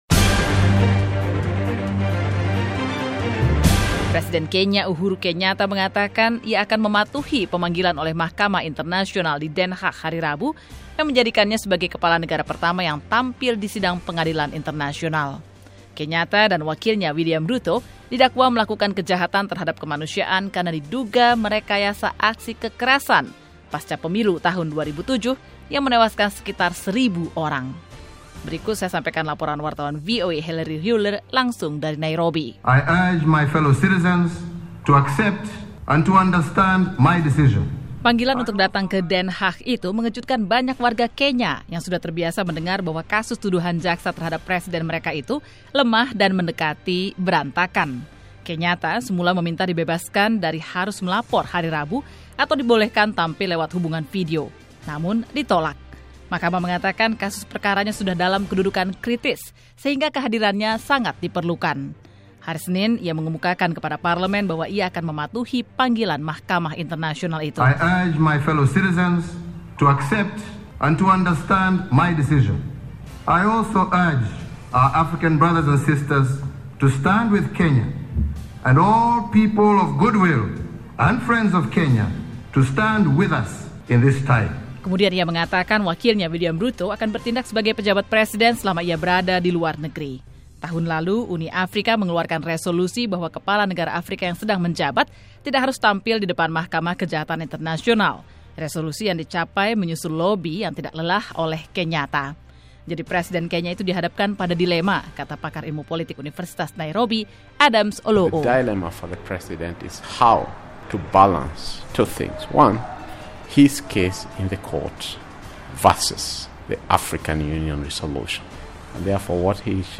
Presiden Kenya - Uhuru Kenyatta - siap tampil di sidang Mahkamah Kriminal Internasional hari Rabu, menjadikannya sebagai kepala negara pertama yang hadir di sidang ICC. Yang menarik sebagian anggota parlemen akan mendampinginya. Berikut laporannya.